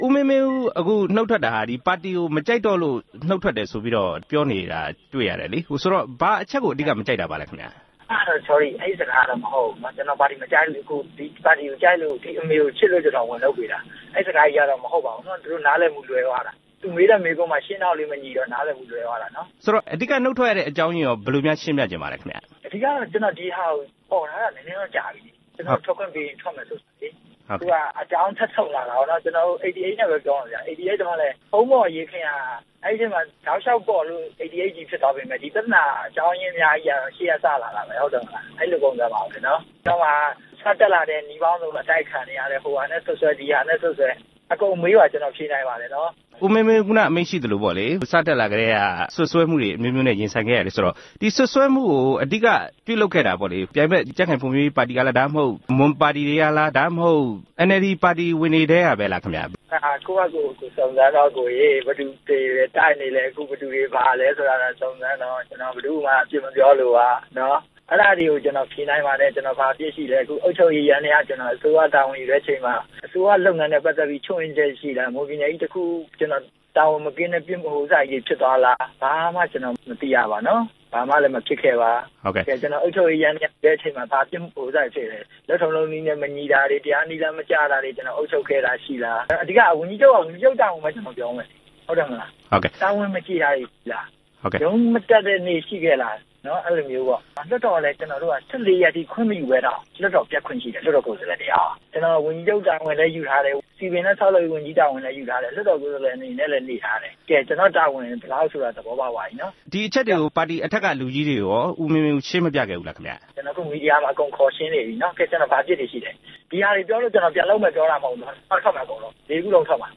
နှုတ်ထွက်တော့မယ့် မွန်ပြည်နယ် ဝန်ကြီးချုပ် ဦးမင်းမင်းဦးနဲ့ RFA မေးမြန်းချက်